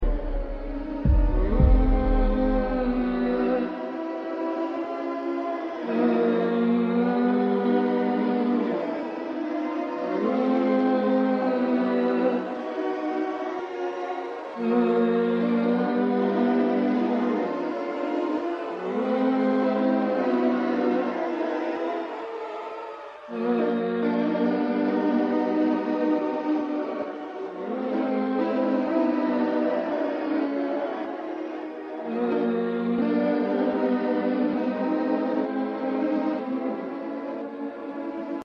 Scary Hit Sound Button: Unblocked Meme Soundboard